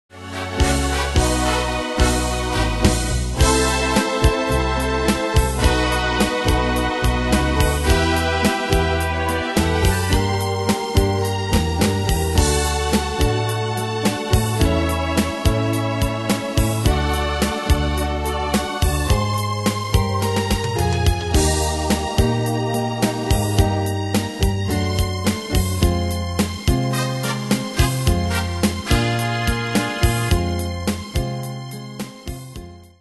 Style: PopFranco Ane/Year: 1973 Tempo: 107 Durée/Time: 2.14
Danse/Dance: Pop Cat Id.
Pro Backing Tracks